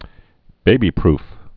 (bābē-prf)